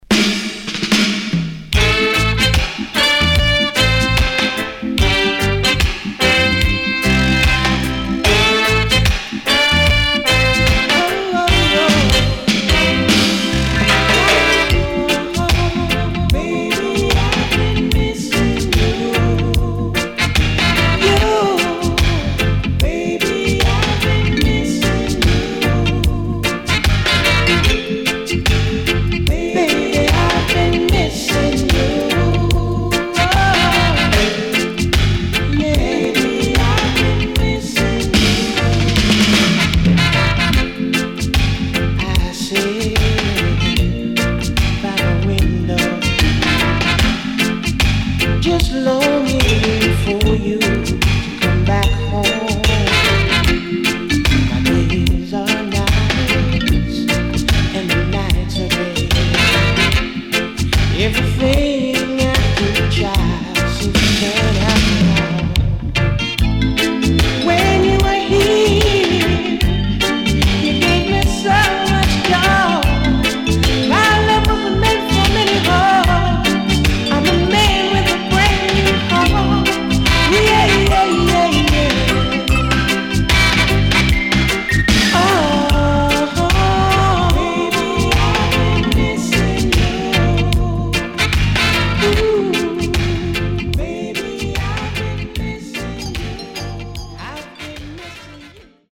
【12inch】